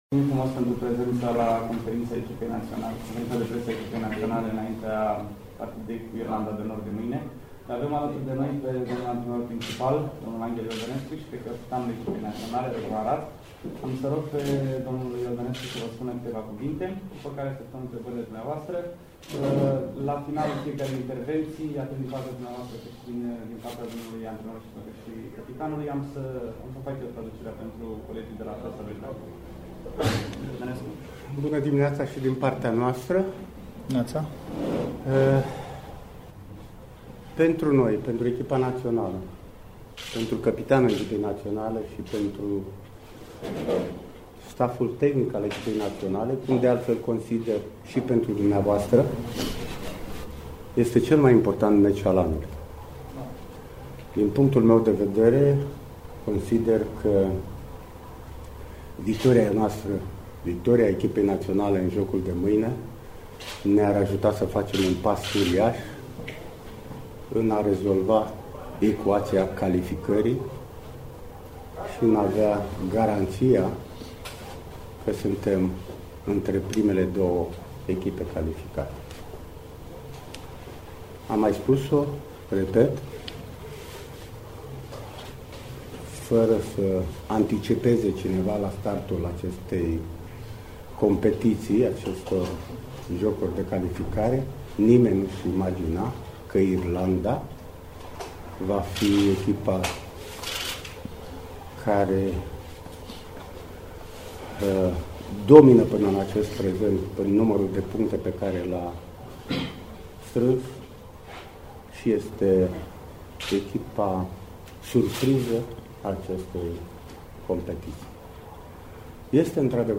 Asa a inceput selecţionerul României, Anghel Iordănescu conferinţa de presă în care a prefaţat duelul cu Irlanda de Nord, de maine din preliminariile pentru Euro 2016.
”Pentru echipa națională, pentru căpitanul echipei naționale, pentru întreg staff-ul, pentru întreaga nație este cel mai important meci al anului. Victoria de mâine ne-ar ajuta să facem un pas uriaș spre calificare și garanția că vom fi între primele două echipe calificate. La începutul campaniei, nimeni nu credea că Irlanda de Nord va ajunge aici, cu atât de multe puncte. Este un derby, am observat că și băieții sunt concentrați”, a declarat Anghel Iordănescu, într-o conferinţă de presă.